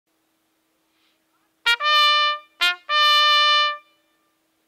CLICK BELOW TO HEAR A SAMPLING OF ACTUAL BUGLE CALLS
USED BY AMERICAN CYCLING CLUBS OF THE 1880'S-90'S